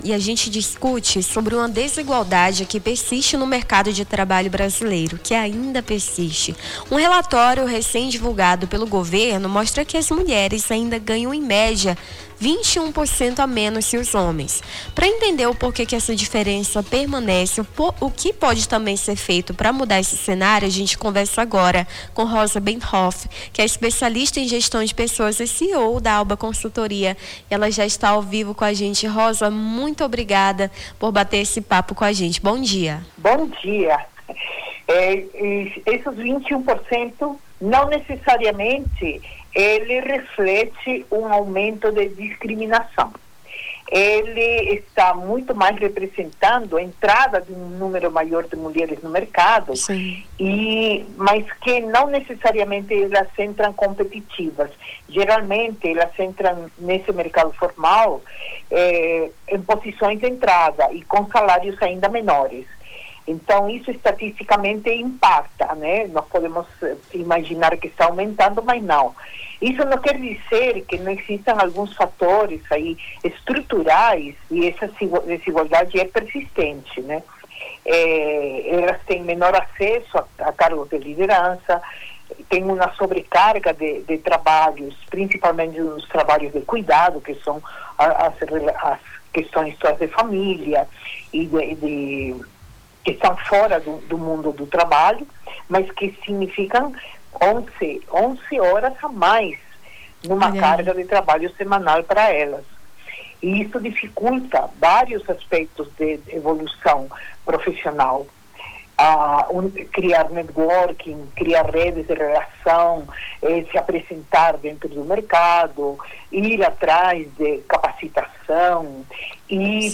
Baixar Esta Trilha Nome do Artista - CENSURA - ENTREVISTA DIFERENÇA SALARIAL HOMENS E MULHERES (10-12-25).mp3 Digite seu texto aqui...